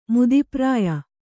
♪ mudi prāya